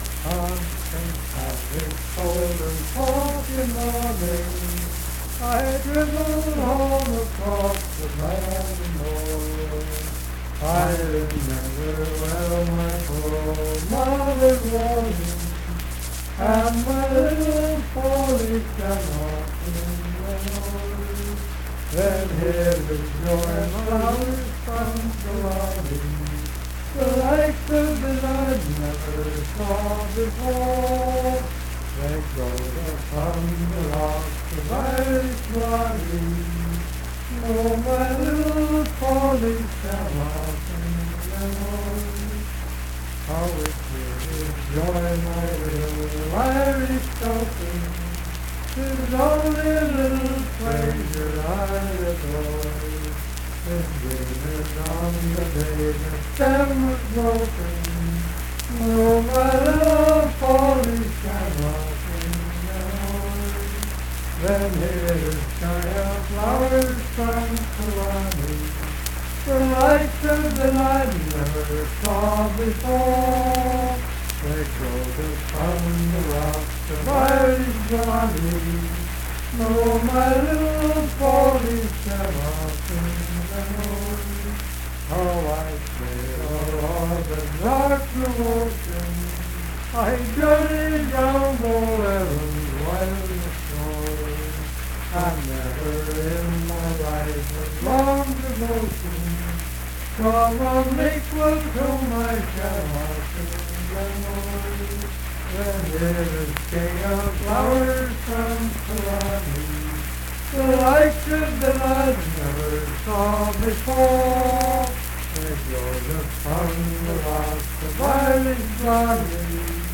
Unaccompanied vocal music
Ethnic Songs
Voice (sung)